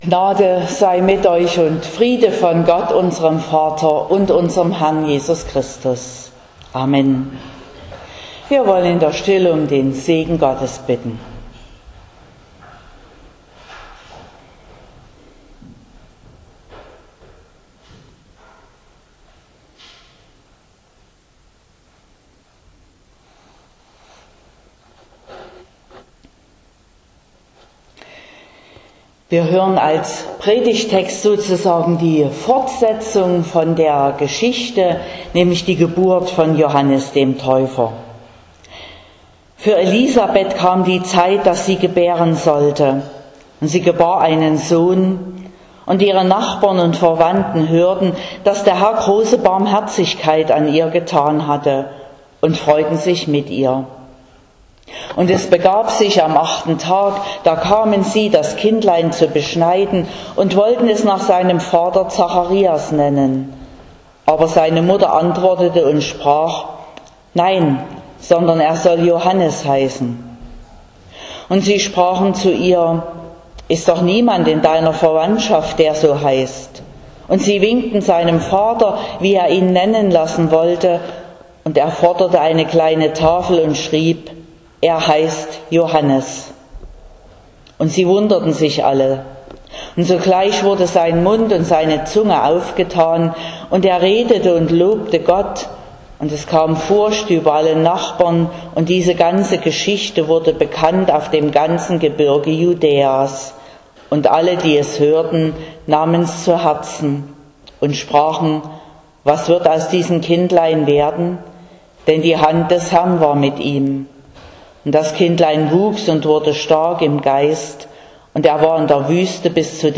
24.06.2021 – Gottesdienst
Predigt und Aufzeichnungen